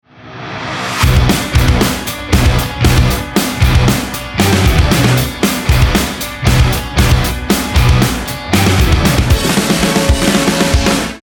此套装适合快速前进并且感觉年轻。
炸弹10秒倒计时